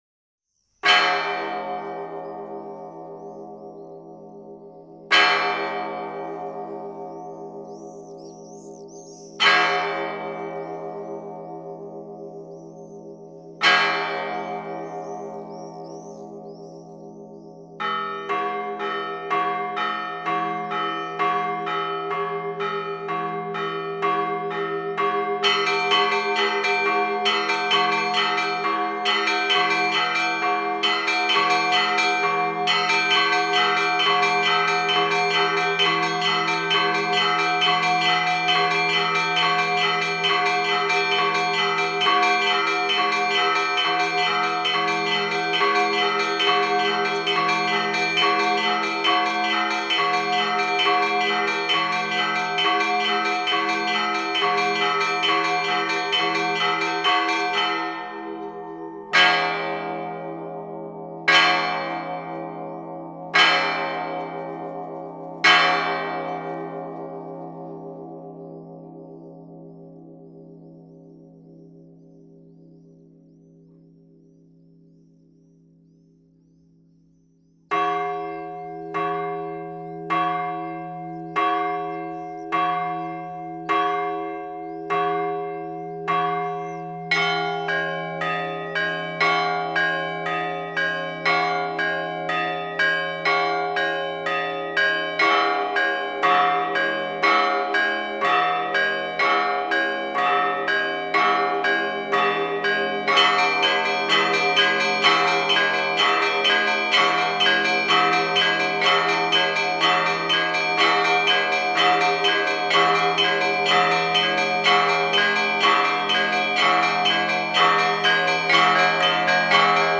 12_Vstrechnyj_Zvon_Budnichnyj_Zvon.mp3